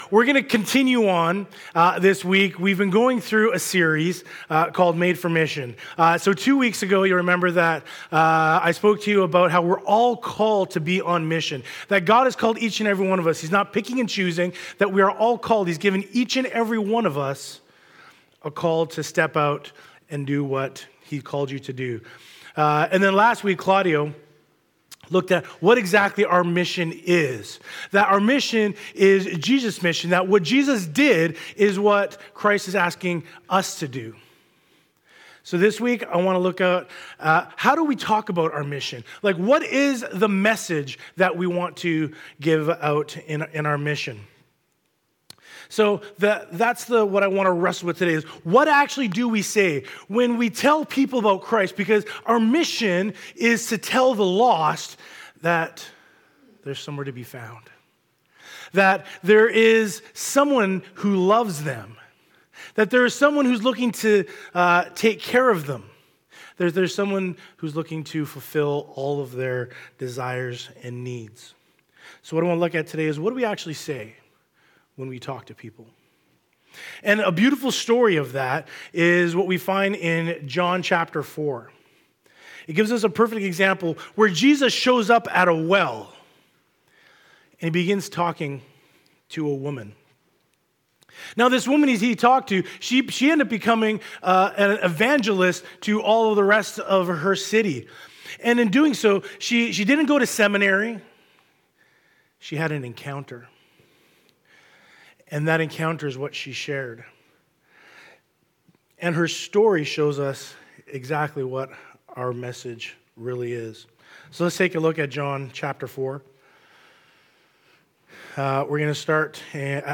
Sermons | City Light Church